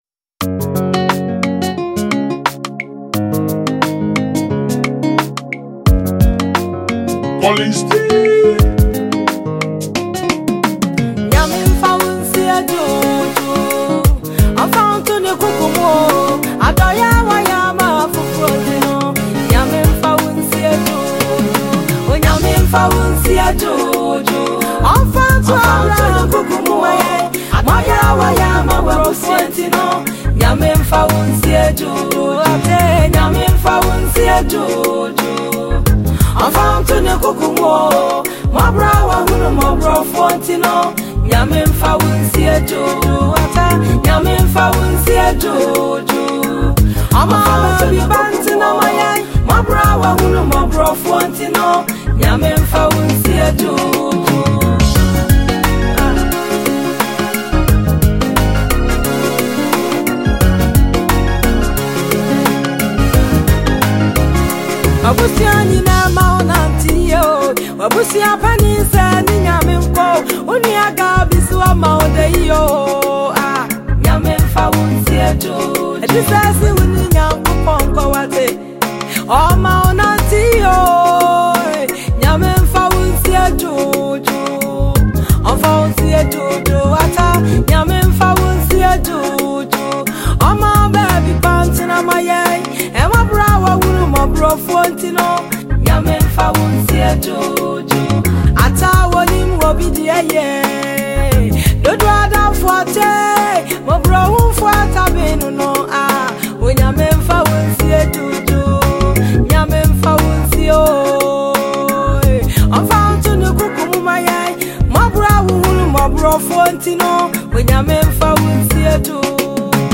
Ghanaian female Gospel singer